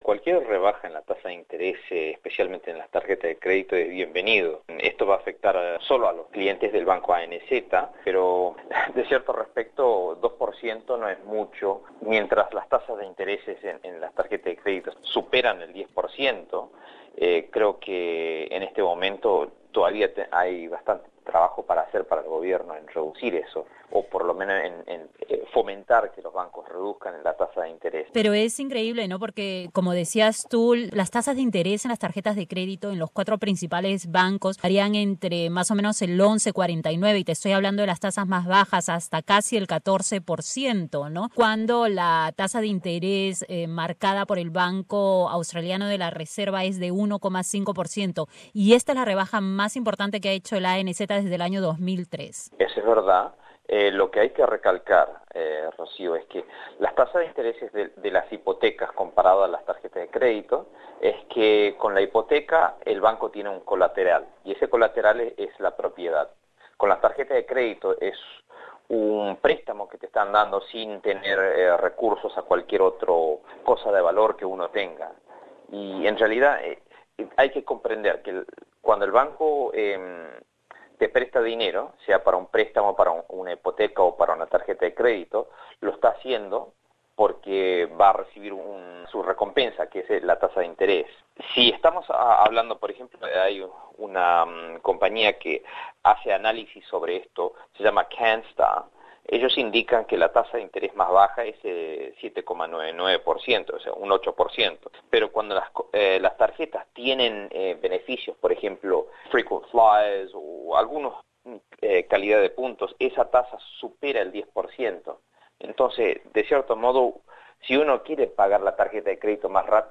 Conversamos con el experto en servicios financieros